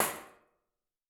TC PERC 02.wav